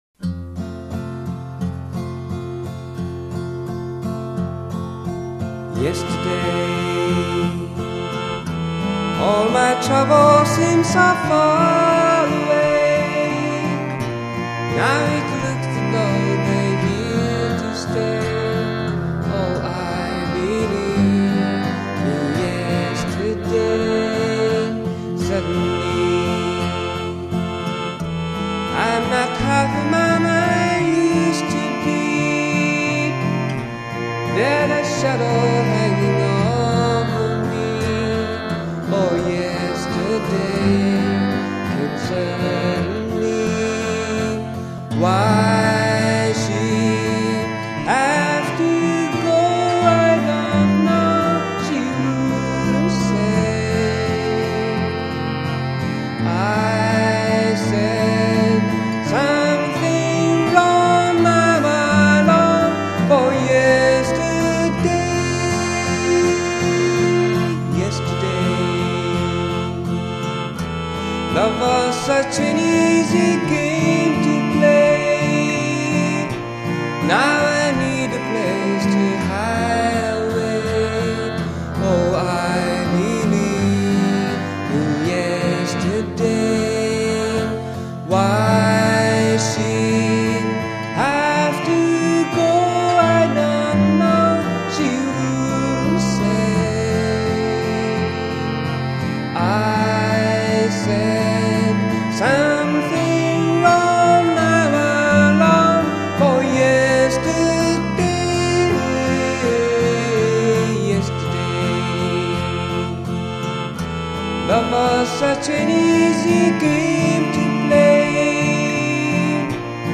「Ｃａｋｅｗａｌｋ」で多重録音および編集、ボーカルマイクにはエフェクターを通している